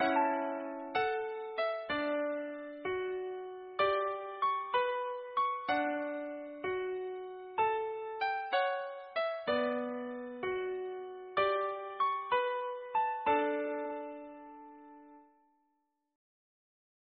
MECHANICAL MUSICAL MOVEMENT
Suitable for deluxe 18 note music box mechanism